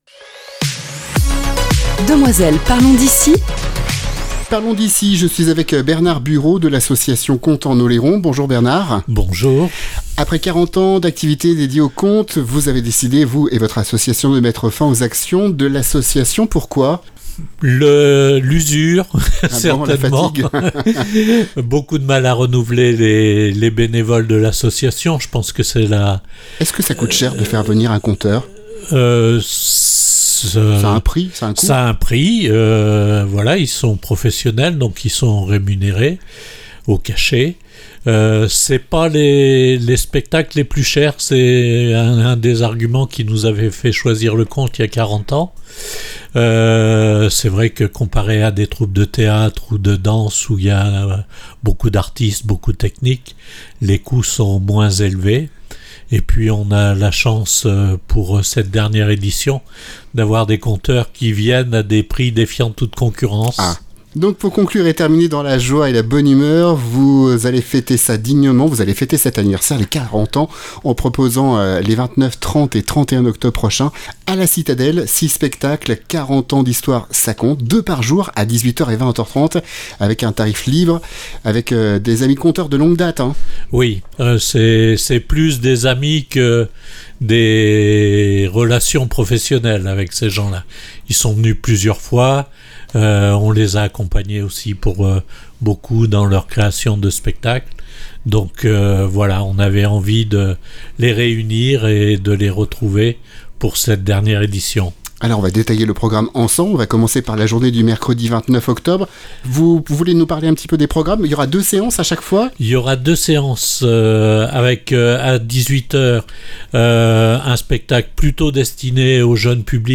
Pour fêter cet évènement comme il se doit, nous avons décider d'organiser trois jours de spectacles avec les conteurs, amis de longue date, qui nous ont accompagnés toutes ces années.